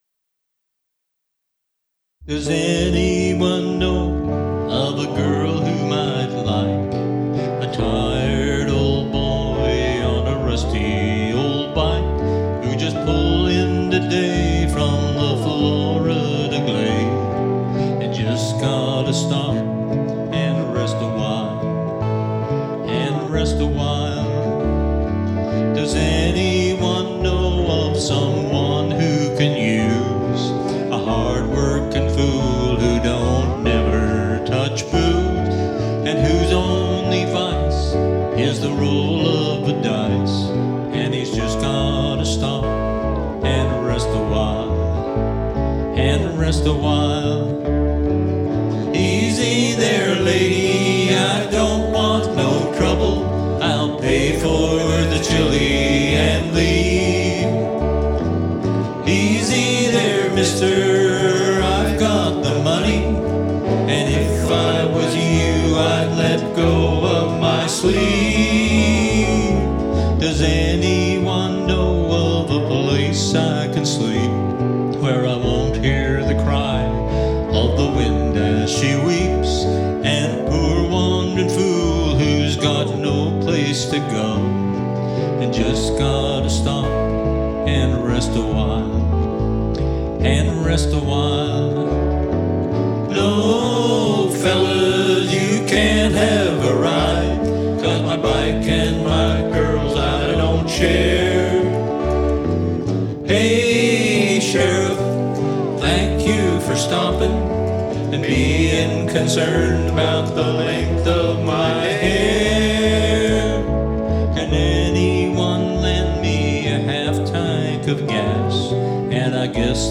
Recording was done on a ZOOM LiveTrak L-12 Mixer/Recorder.
Vocals, Guitar
Lap Steel, Guitar
Bass, Vocals